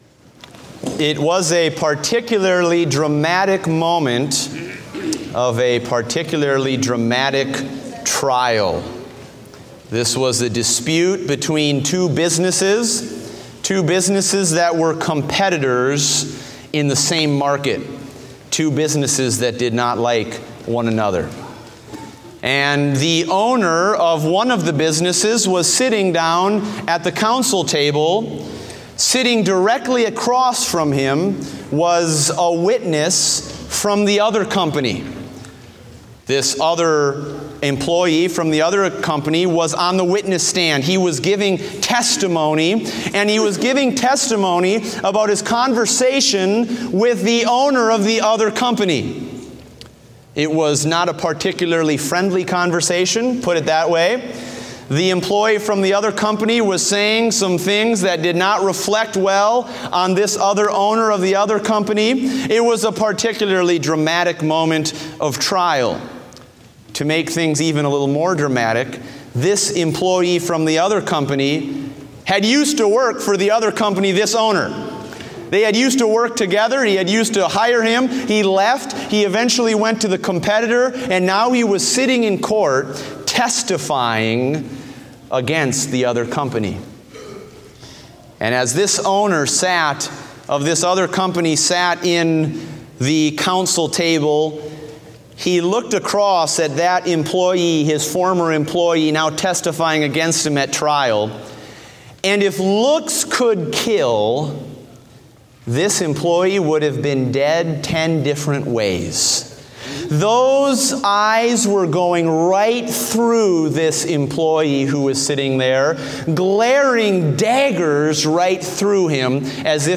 Date: August 28, 2016 (Morning Service)